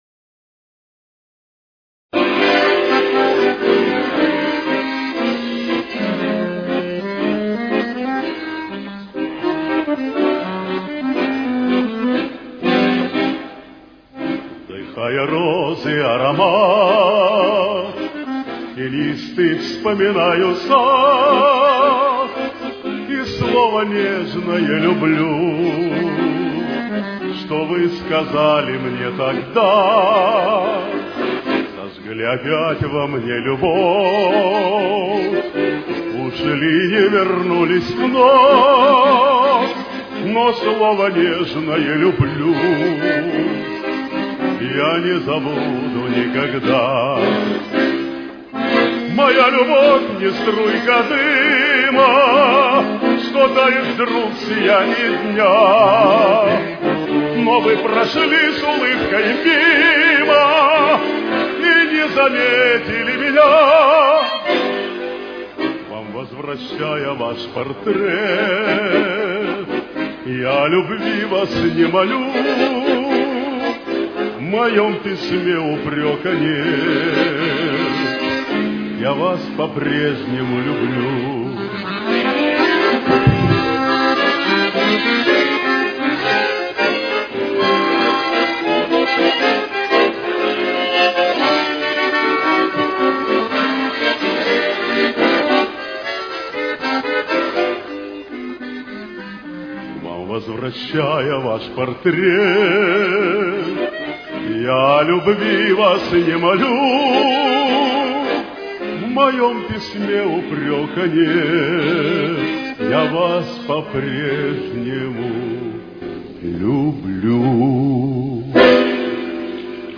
Темп: 112.